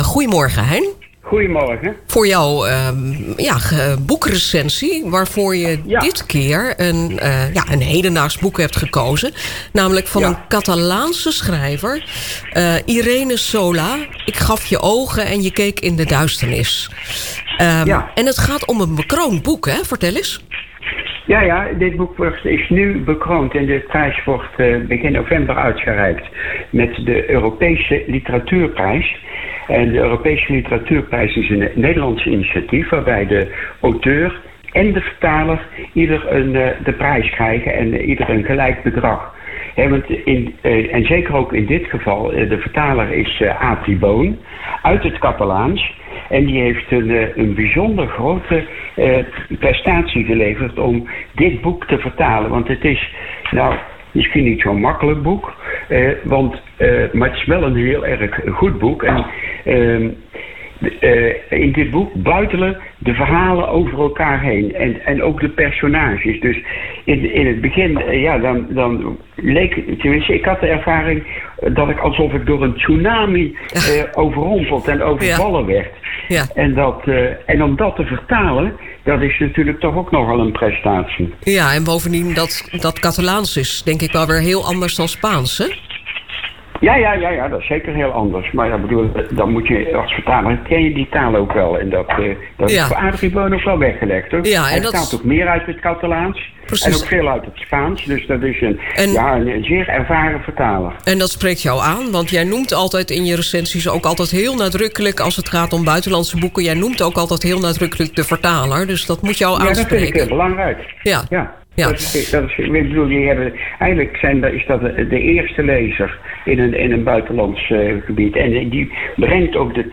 Klik op de afbeelding of op het speakertje om een kort interview op  BredaNu te horen, steeds over een boek.